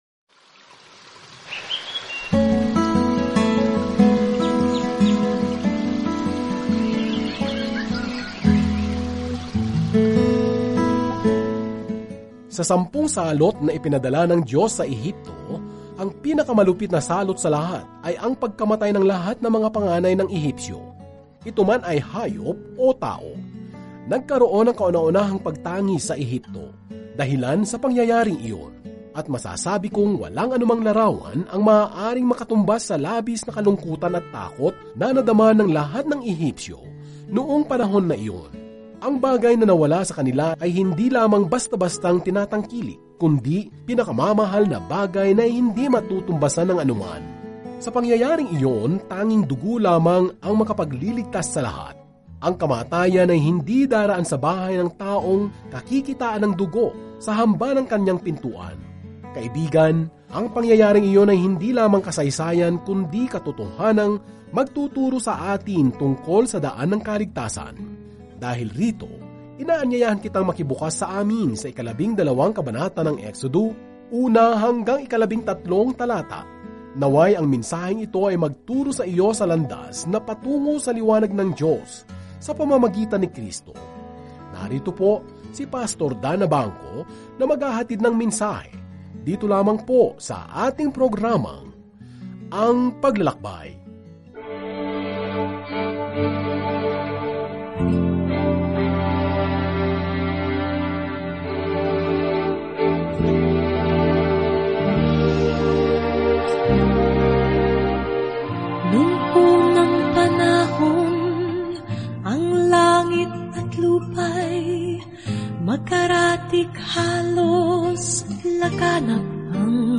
Banal na Kasulatan Exodo 12:1-13 Araw 11 Umpisahan ang Gabay na Ito Araw 13 Tungkol sa Gabay na ito Tinunton ng Exodo ang pagtakas ng Israel mula sa pagkaalipin sa Ehipto at inilarawan ang lahat ng nangyari sa daan. Araw-araw na paglalakbay sa Exodo habang nakikinig ka sa audio study at nagbabasa ng mga piling talata mula sa salita ng Diyos.